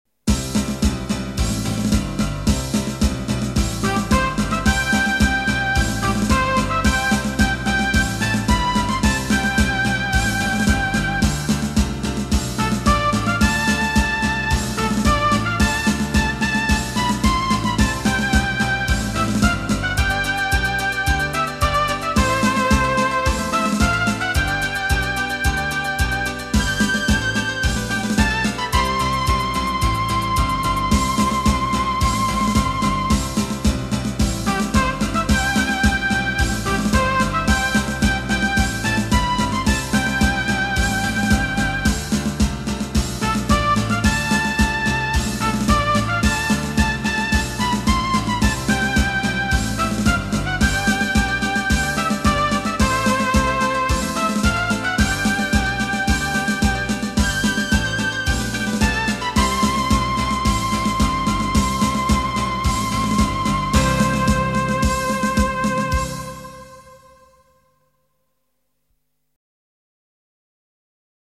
Made in Monday-City (Dushanbe).